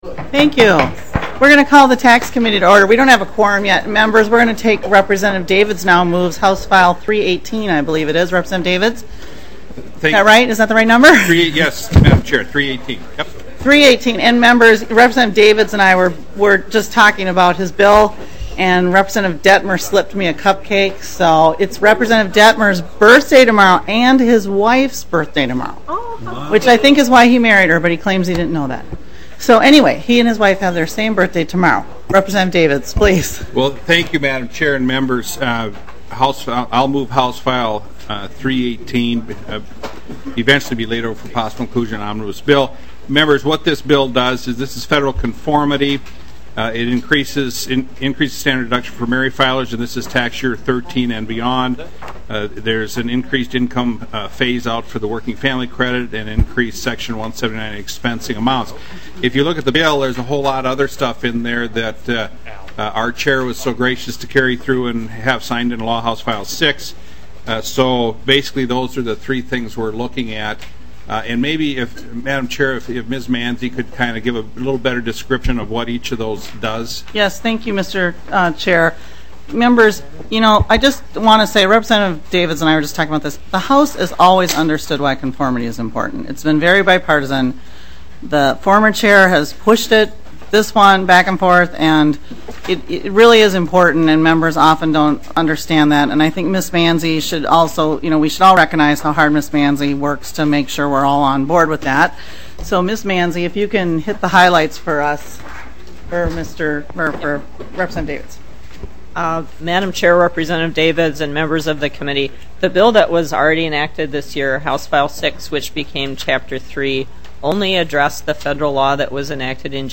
Taxes EIGHTEENTH MEETING - Minnesota House of Representatives